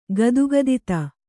♪ gadugadita